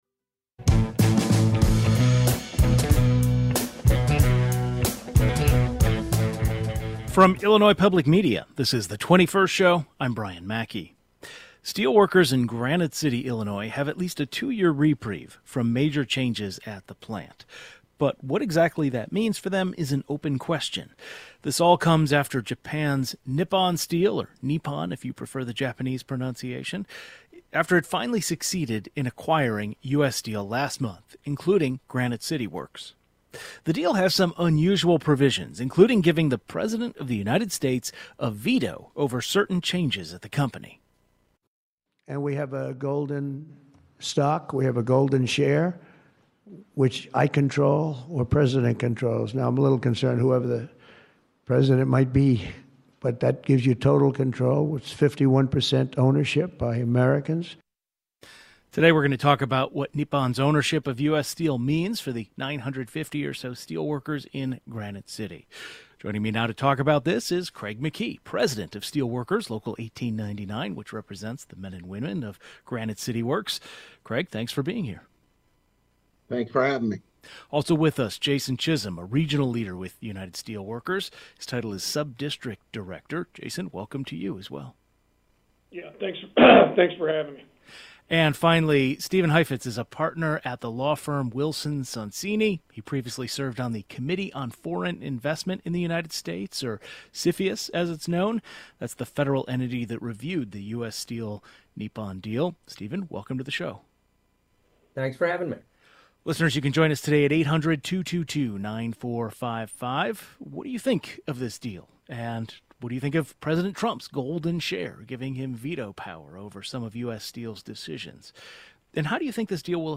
Two union leaders as well a foreign investment expert join the program to discuss what Nippon’s ownership of U.S. Steel means for over 900 steel workers in Granite City.